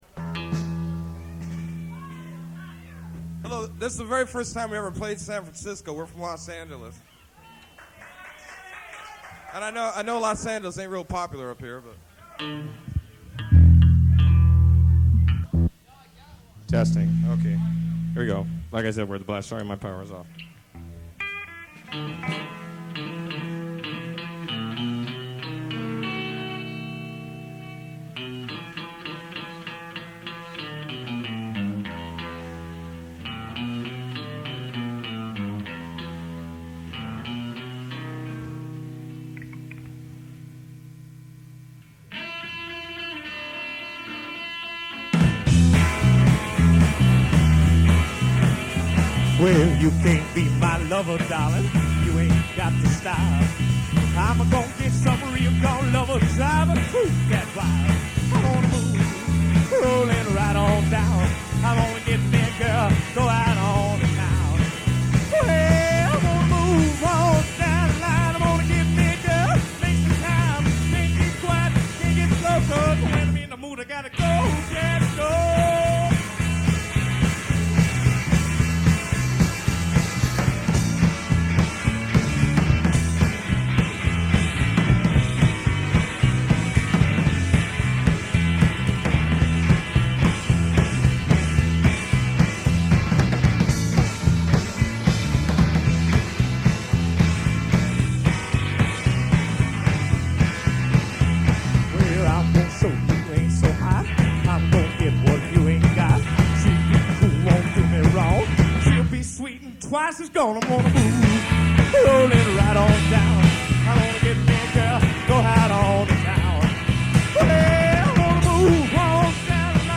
Cowpunk